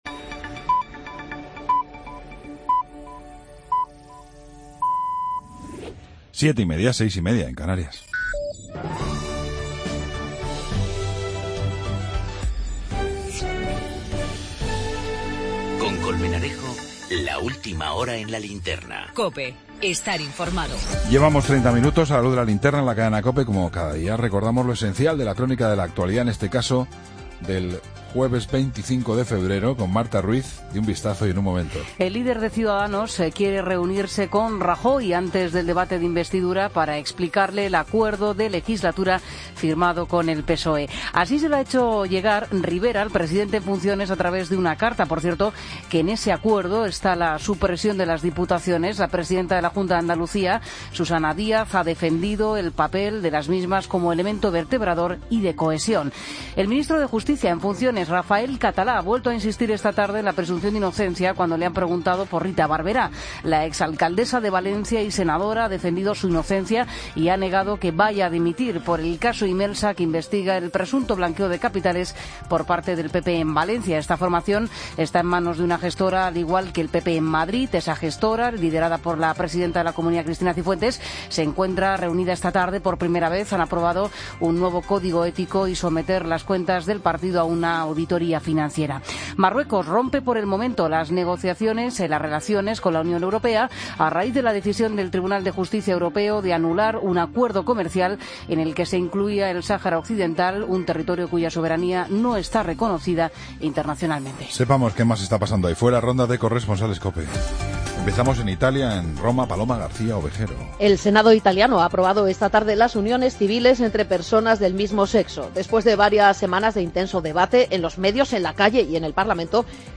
Ronda de corresponsales.